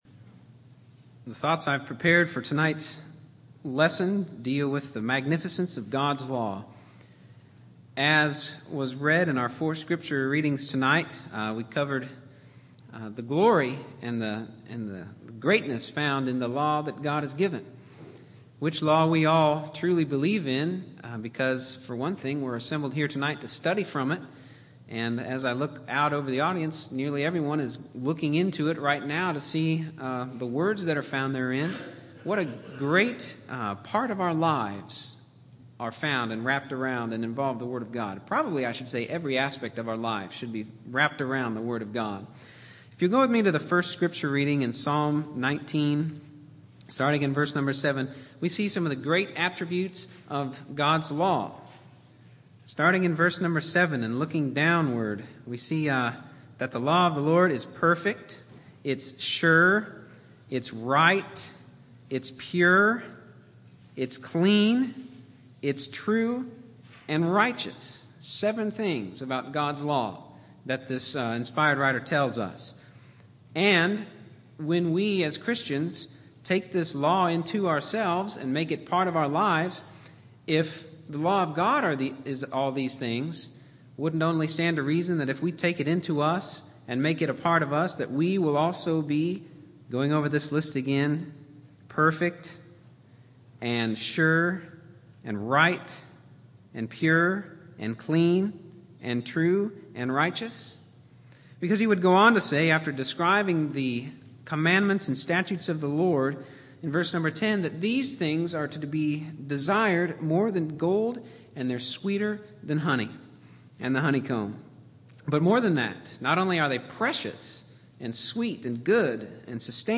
Categories: Sermons Tags: , ,